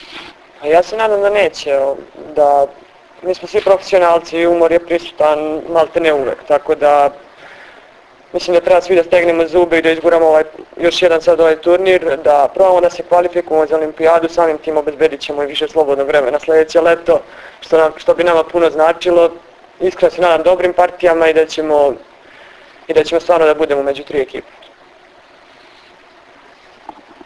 Tim povodom danas je u beogradskom hotelu “M” održana konferencija za novinare
IZJAVA